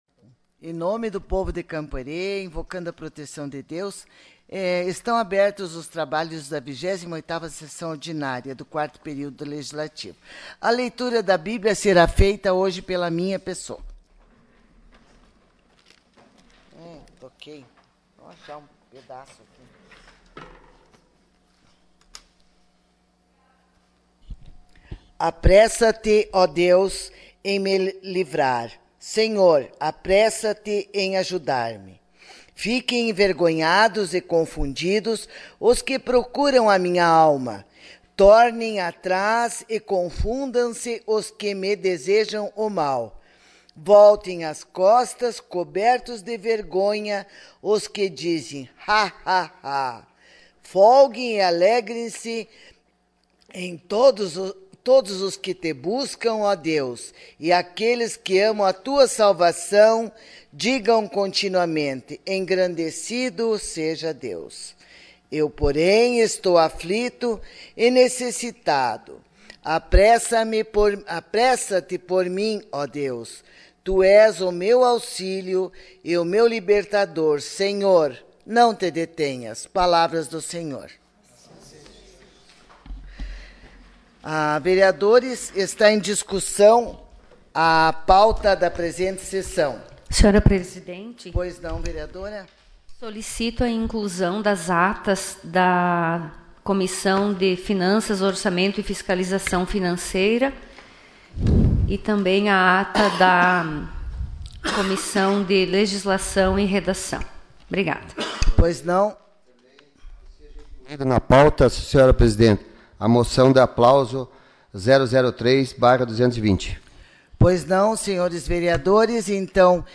SESSÃO ORDINÁRIA DIA 18 DE SETEMBRO DE 2020